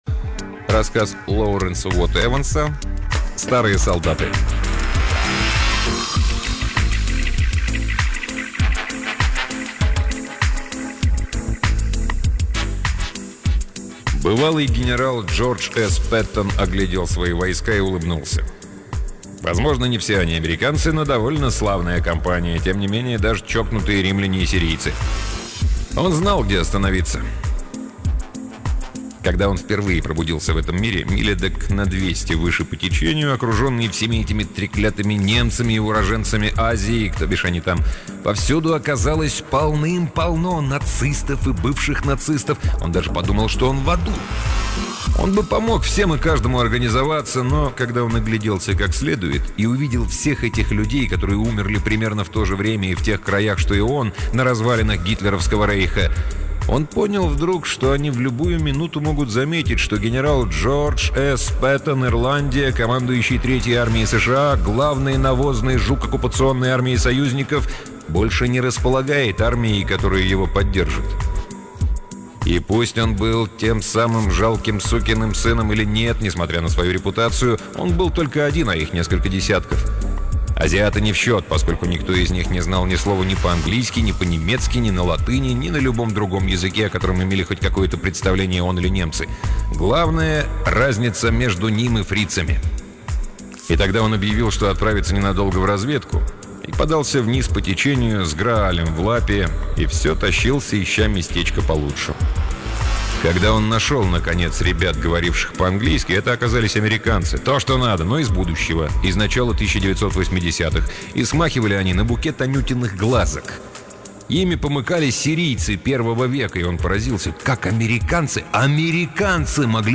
Аудиокнига Лоуренс Уотт-Эванс — Старые солдаты